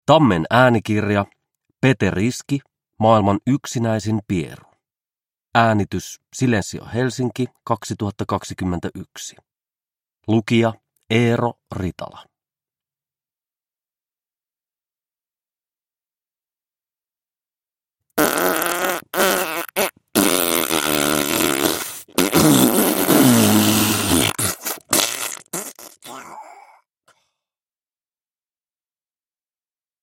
Maailman yksinäisin pieru – Ljudbok – Laddas ner
Uppläsare: Eero Ritala